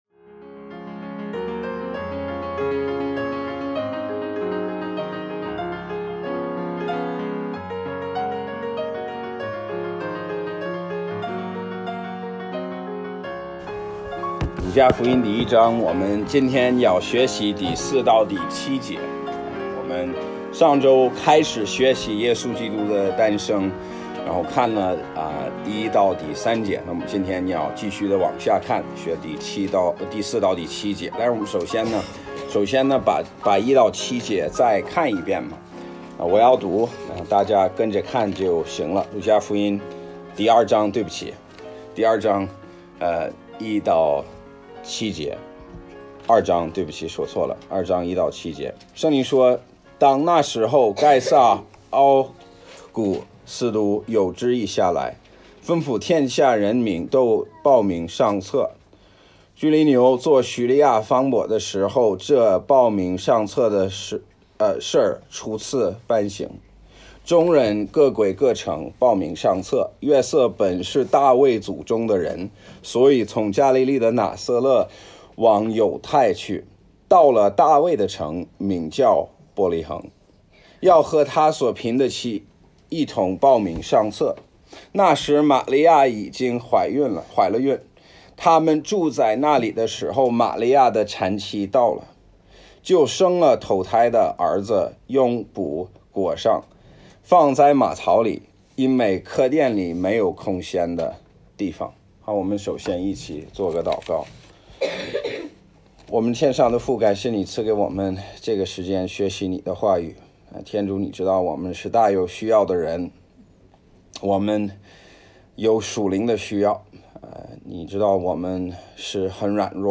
Bible Text: 路加福音2:1-7 | 讲道者